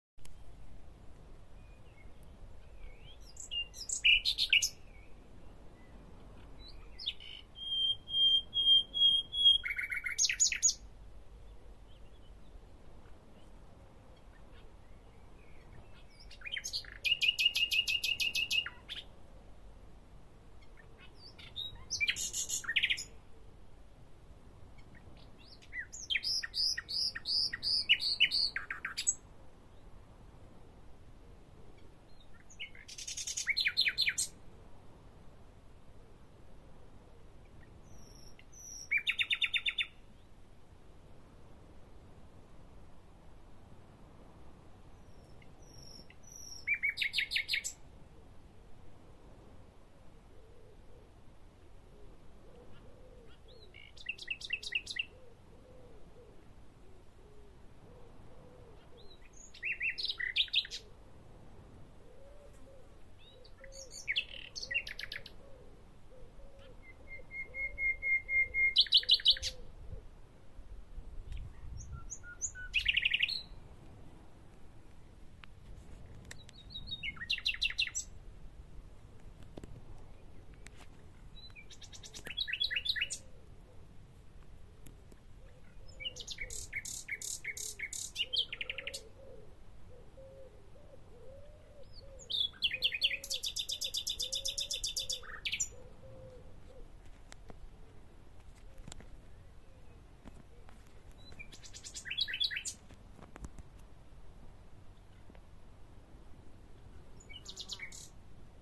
Nachtegaal geluid
Deze vogels gebruiken een reeks geluiden, van hoge trillers tot complexe melodieën, om te communiceren.
Deze duren tussen de 2 en 4 seconden en zijn rijk aan klankkleur.
Deze vogel produceert melodieën die rijk zijn aan variatie. Je hoort fluitende tonen en snelle trillers.